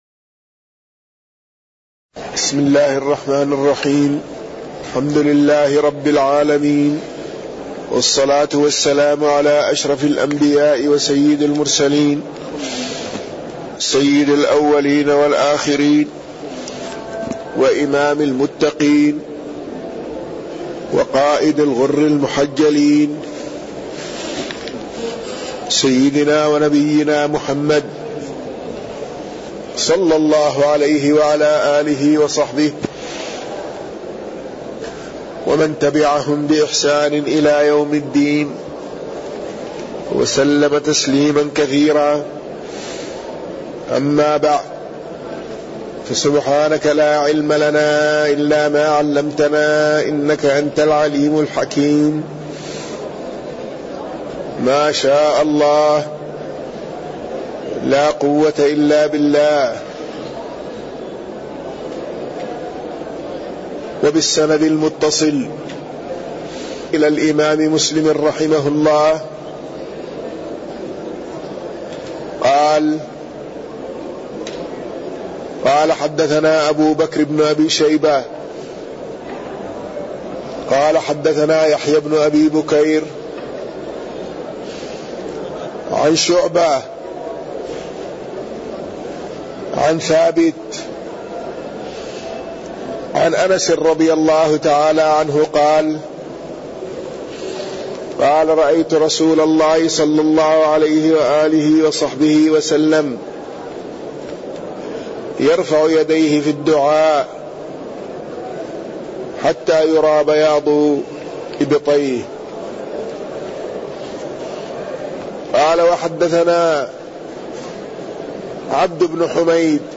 تاريخ النشر ٢٤ شوال ١٤٣١ هـ المكان: المسجد النبوي الشيخ